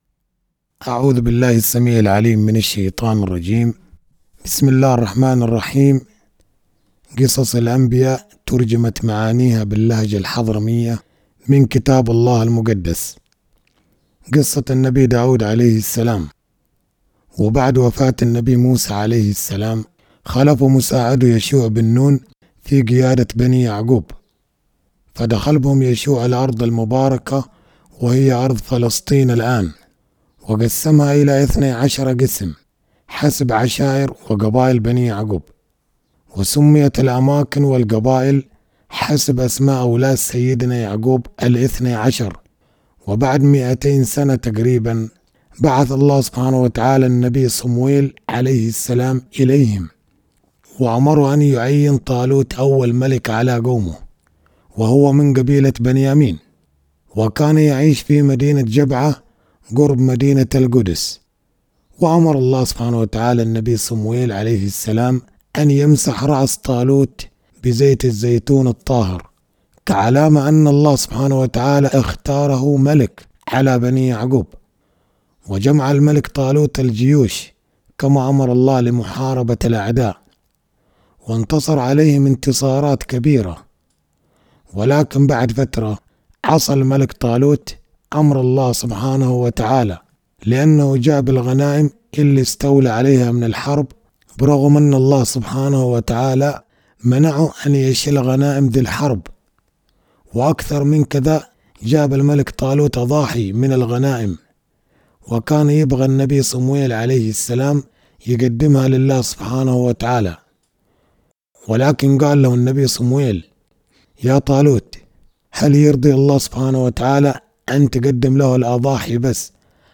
قصة النبي صموئيل والنبي داود | قصص الأنبياء باللهجة الحضرمية